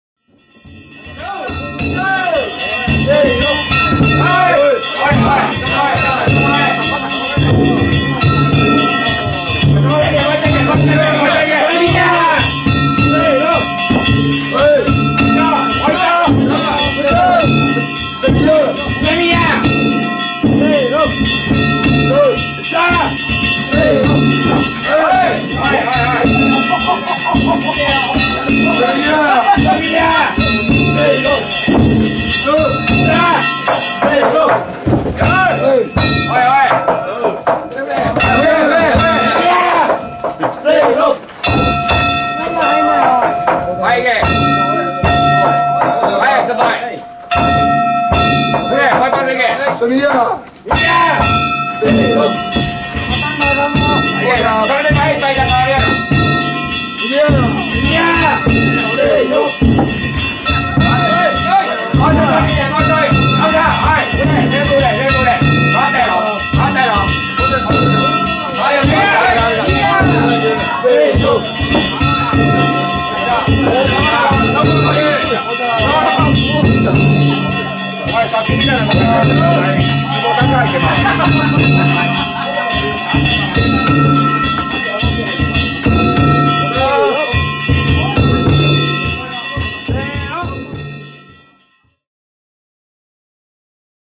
平成２９年７月１５日、生野区の四條地車夏祭り曳行を見に行ってきました。
お囃子の音が聞こえてきます。
軽快な道中囃子、そしてところどころで手打ちのお囃子を囃しながら進みます。
道中囃子と手打ちの囃子が続きます。